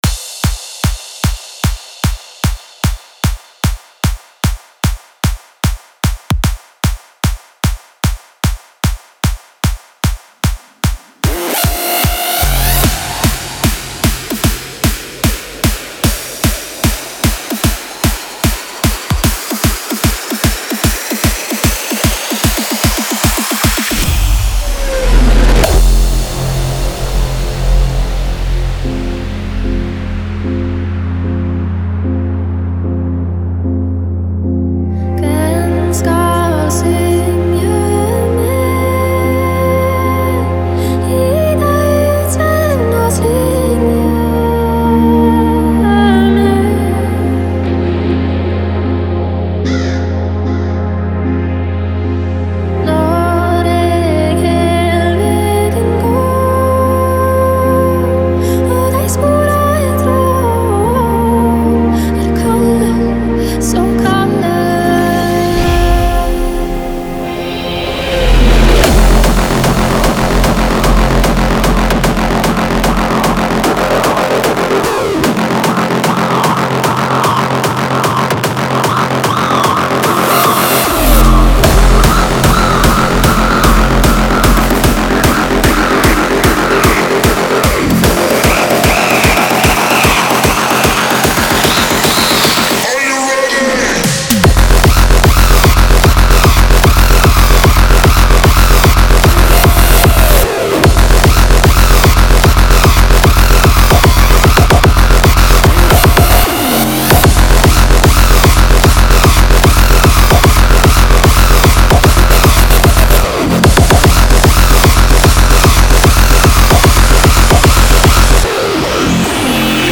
Type: Serum Samples